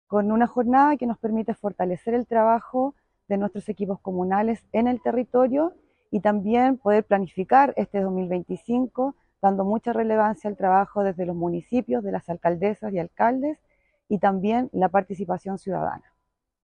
Directora-Regional-SENDA-1.mp3